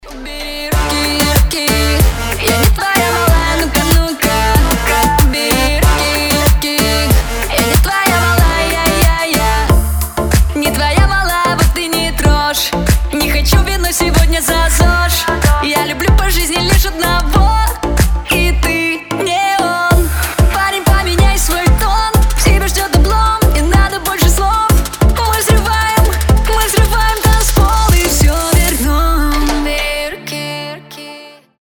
• Качество: 320, Stereo
забавные
озорные